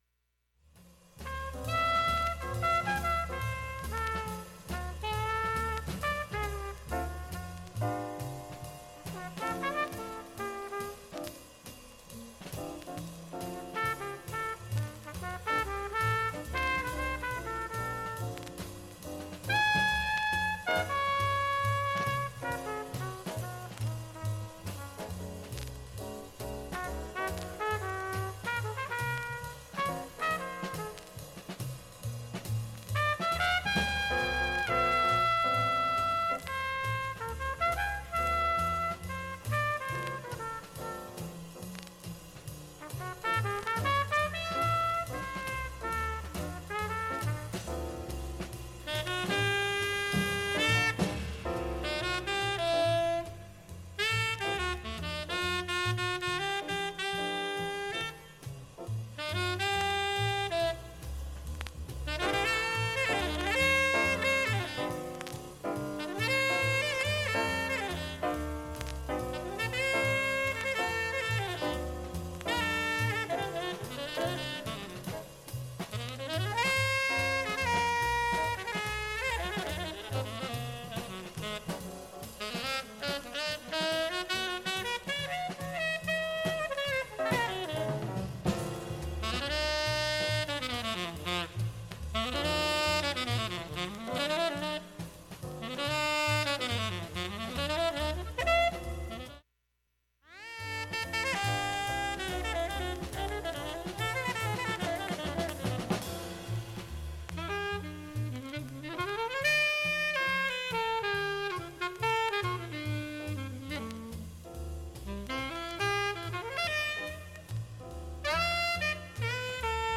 全体の音質自体はクリアないい状態です。
静かな部でも嫌なチリプツなどはありません。
A-1中盤に2ミリスレ5本でチリプツ出ます。
A-1プツは聴こえないレベルが大半です。
A-2後半からA面最後まではかなり良い音質です。
現物の試聴（上記録音時間６分）できます。音質目安にどうぞ